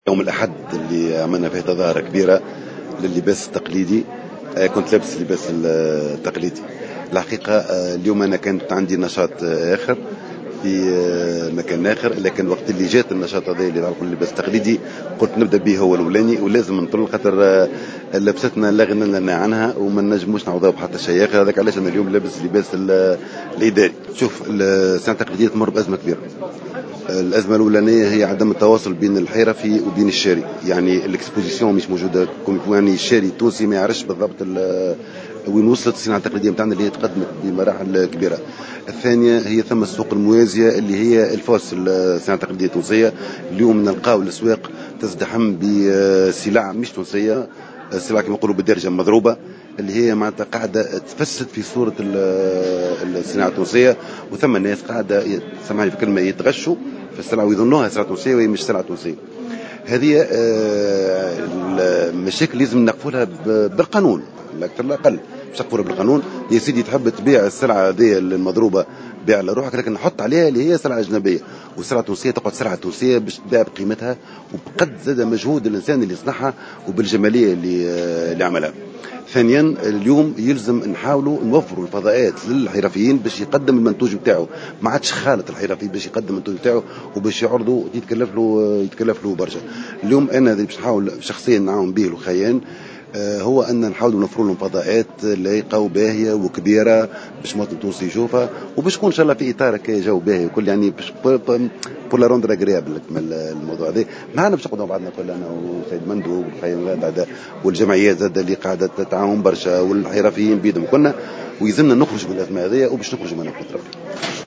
وأكّد الوالي في تصريح لمراسل الجوهرة اف ام، أن الصناعات التقليدية تمر بأزمة كبيرة، لعل من أبرز أسبابها عدم التواصل بين الحرفي والحريف، وازدحام الفضاءات التجارية بمنتوجات الأسواق الموازية التي تهدّد منتوجنا التونسي وأصالته.